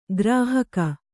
♪ grāhaka